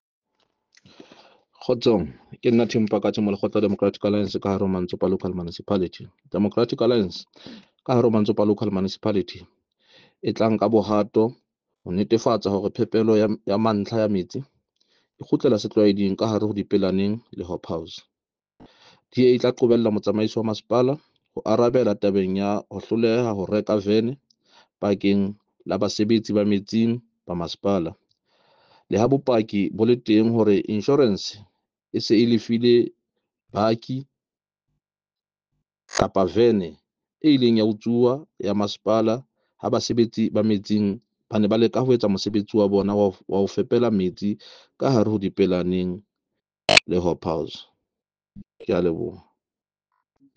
Sesotho soundbites by Cllr Tim Mpakathe